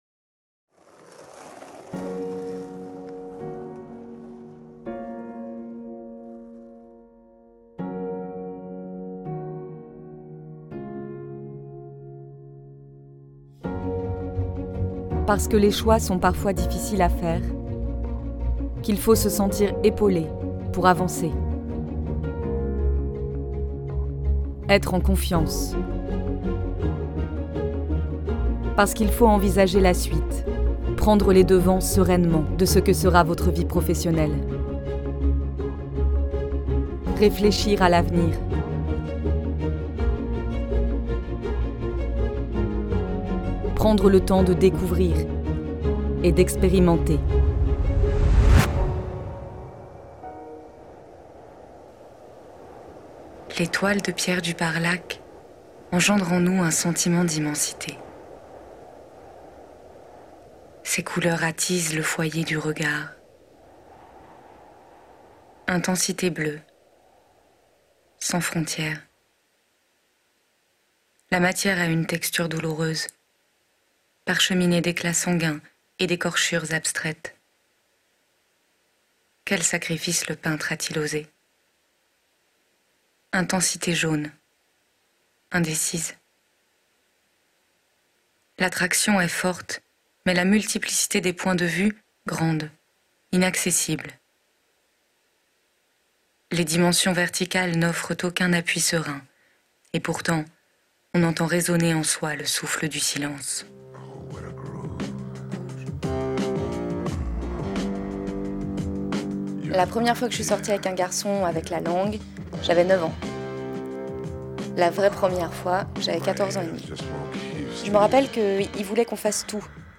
Voix off
- Mezzo-soprano